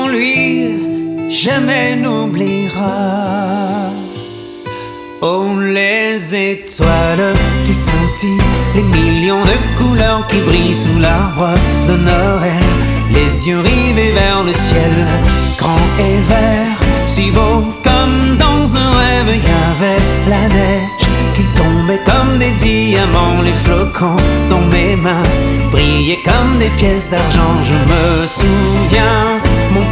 • Musique francophone